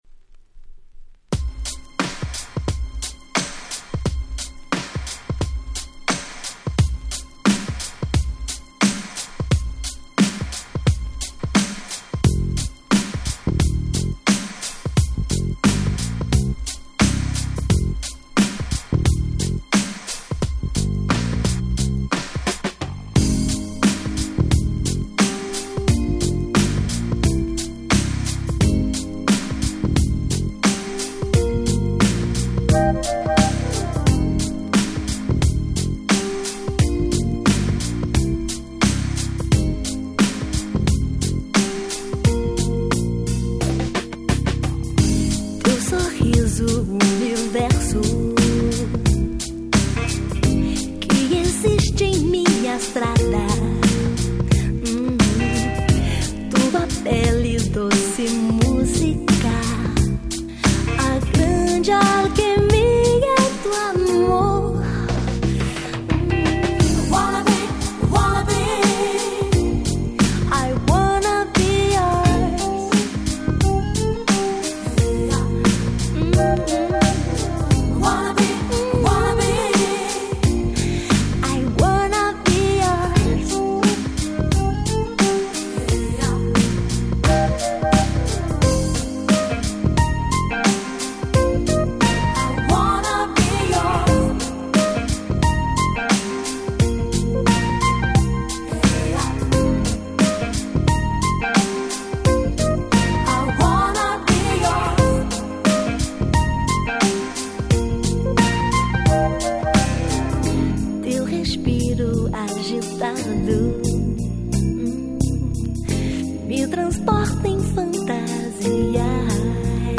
97' Very Nice EU R&B !!
R&Bあり、Houseあり、Bossaあり、Popsありの良曲だらけの最高の1枚。
R&B的には大ネタも飛び出すMid Dancer